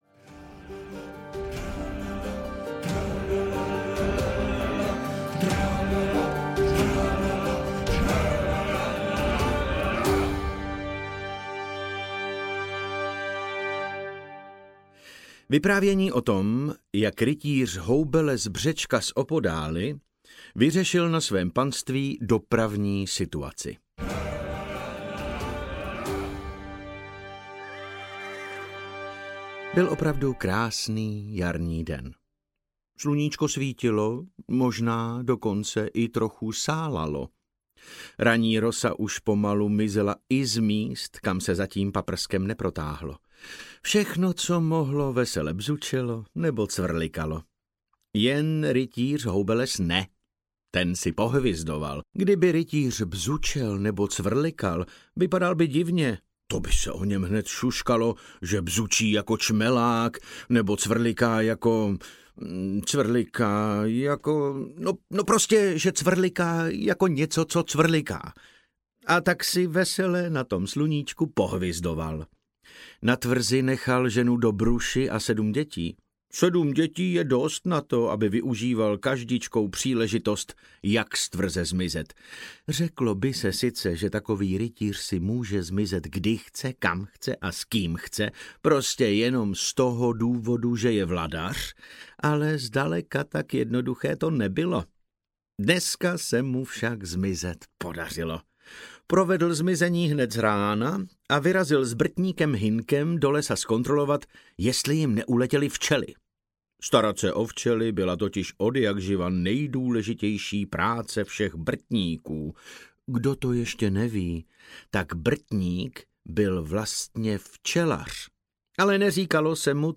Houbeles audiokniha
Ukázka z knihy
• InterpretDavid Novotný